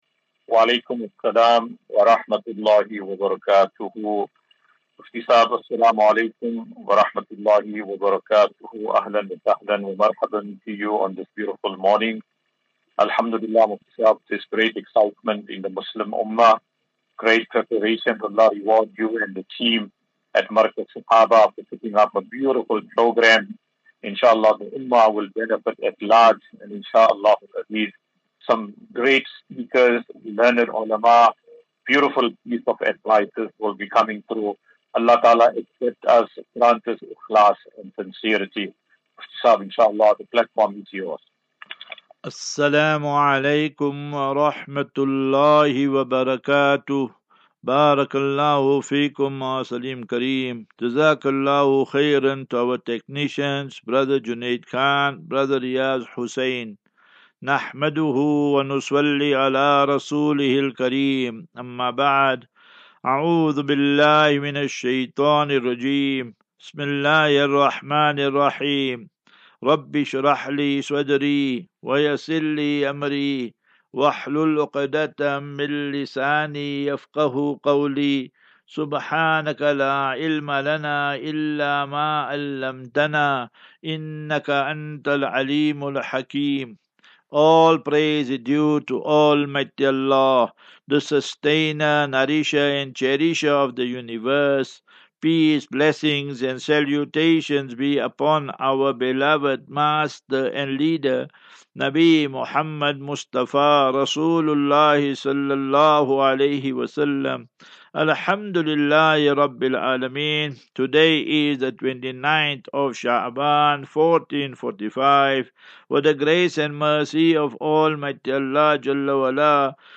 Daily Naseeha.
As Safinatu Ilal Jannah Naseeha and Q and A 11 Mar 11 March 2024.